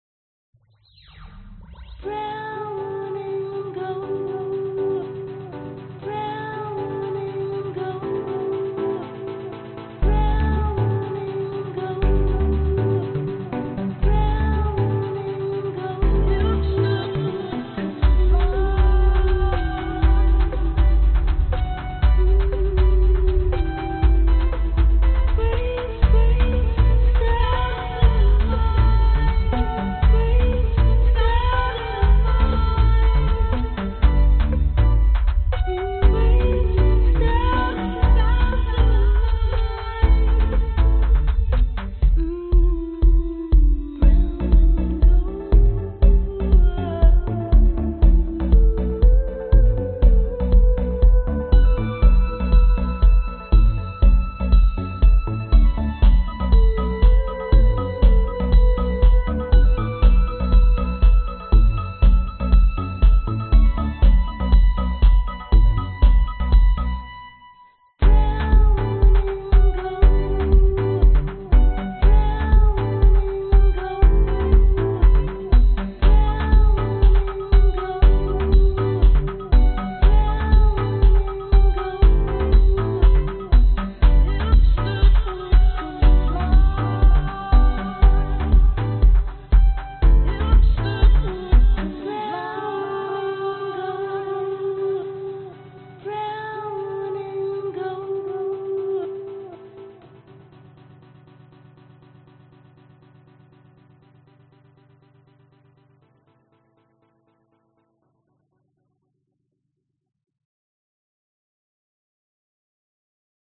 描述：混合，也是来源
标签： 女声 房子 电子 吉他 贝司 合成器 循环 钢琴